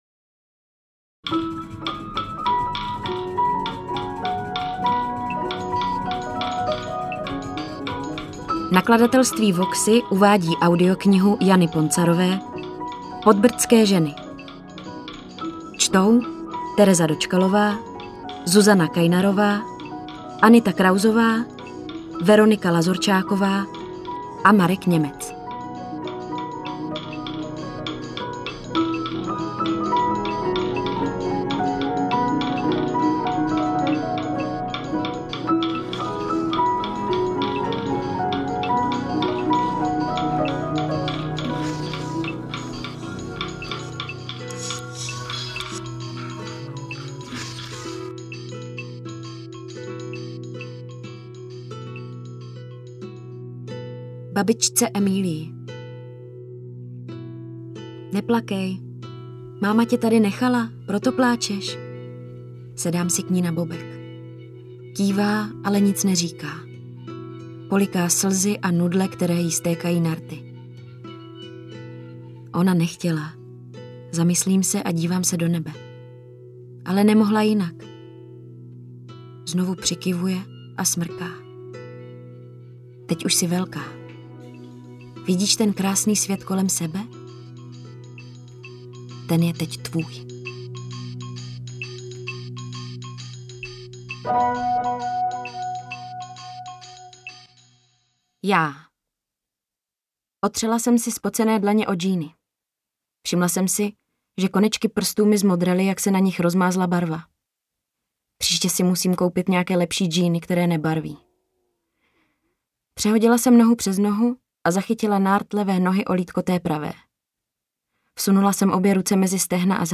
AudioKniha ke stažení, 54 x mp3, délka 15 hod. 22 min., velikost 839,8 MB, česky